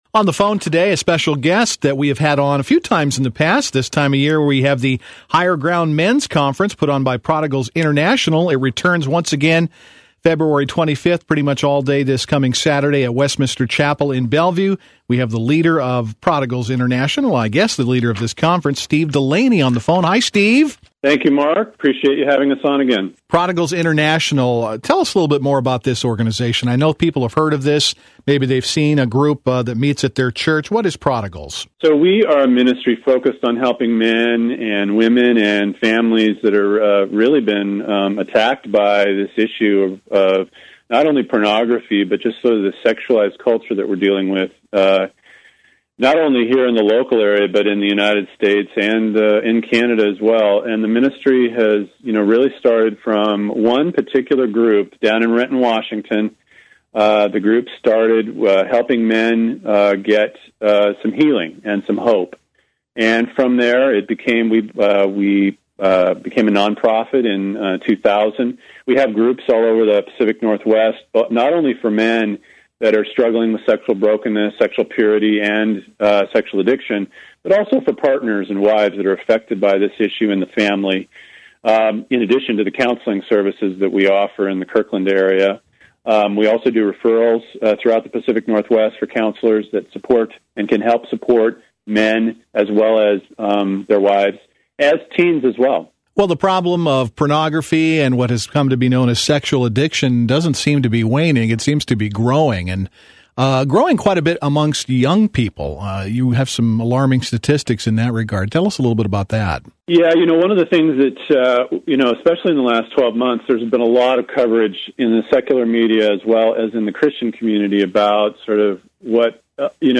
Interview
KCIS 630 AM